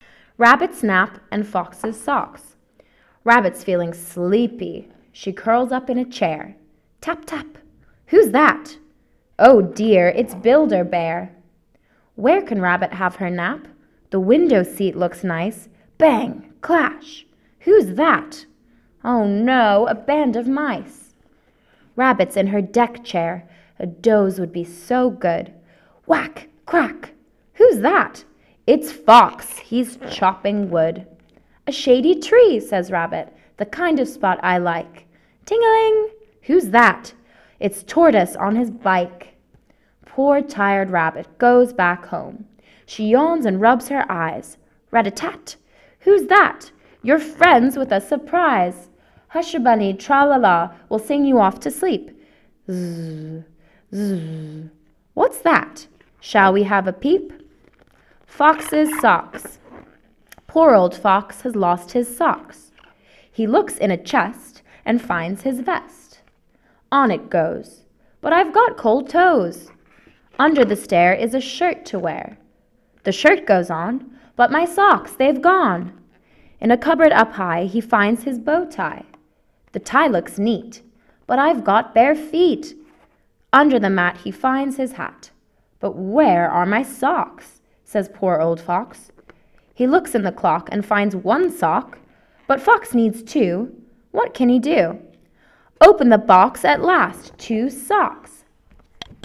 Rabbit's nap + Fox's socks américain